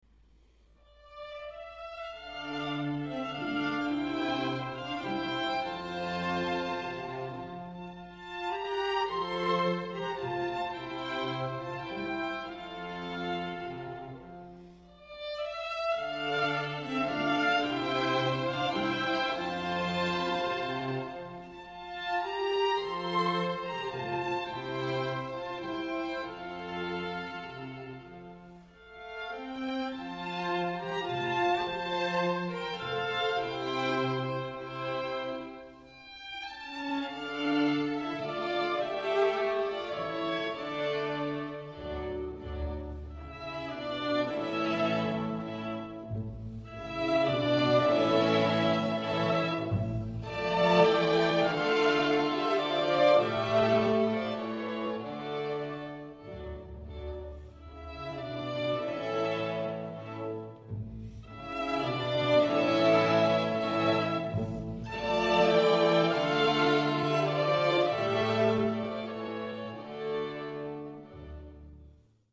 Suggestioni rinascimentali
Dalle Antiche arie e danze per liuto di Ottorino Respighi proponiamo la Siciliana, dal gusto nobile e raffinato.